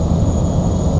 turbo_use.ogg